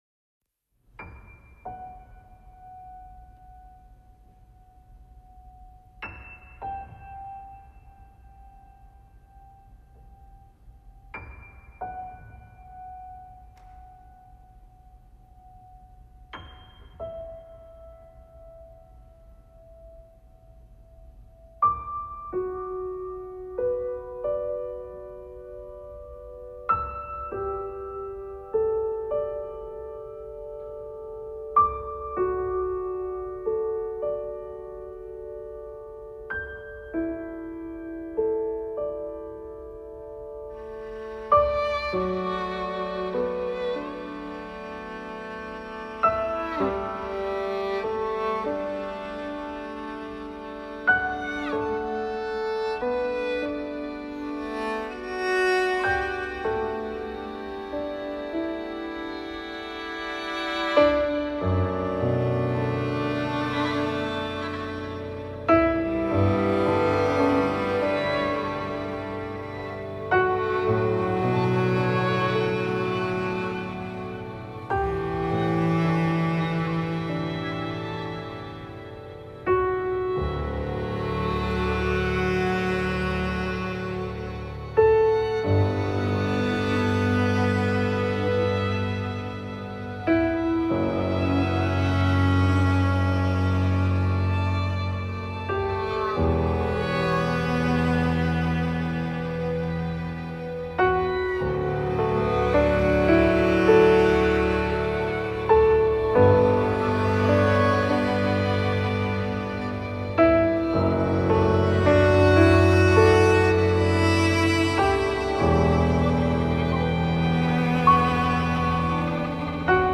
آهنگهای پاپ فارسی
موزیک بی کلام